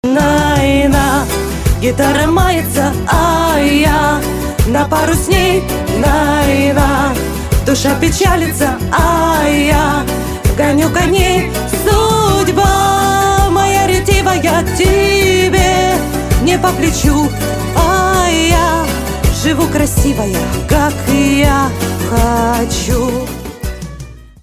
• Качество: 160, Stereo
скрипка
кавер